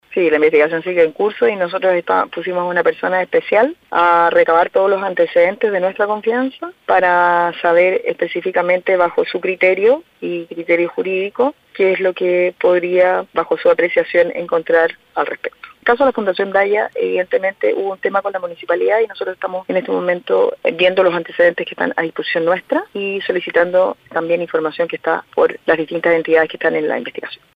Al respecto, en diálogo con La Radio, la alcaldesa entrante de Quilpué, Carolina Corti, aseguró que la investigación en el municipio sigue en curso, buscando que los antecedentes estén a su disposición, en pro de la transparencia.